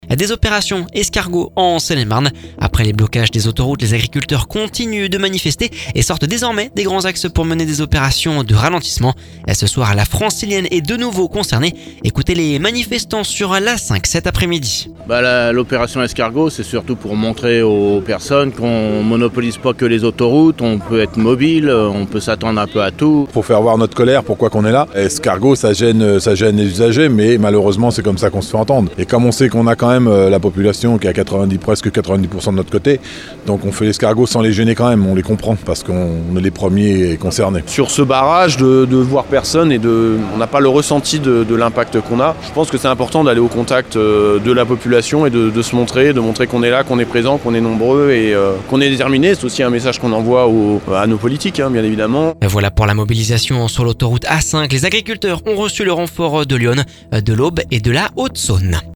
Ecoutez les manifestants sur l’A5 cet après-midi…